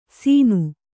♪ sīnu